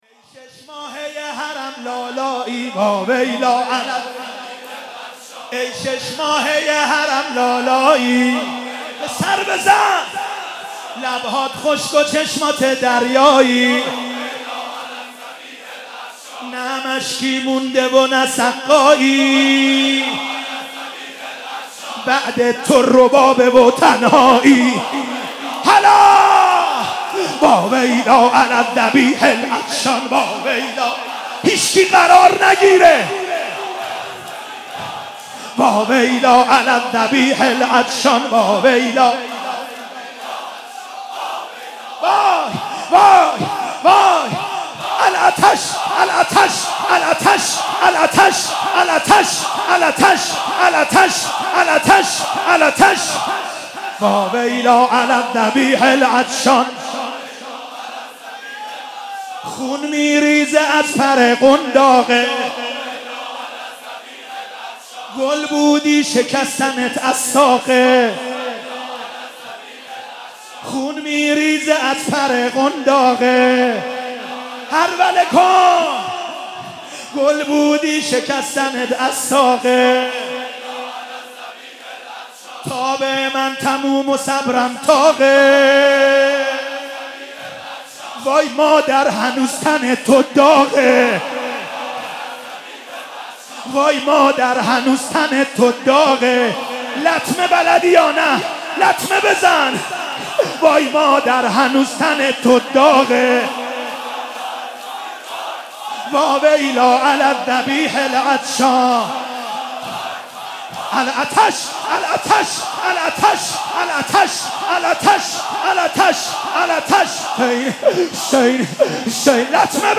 شب هفتم محرم95/هیئت رزمندگان اسلام قم